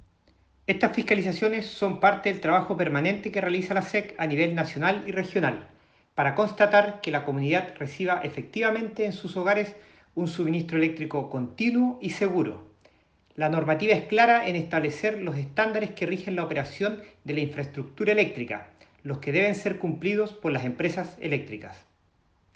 Audio: Jorge Sandoval, Director Regional de SEC Arica y Parinacota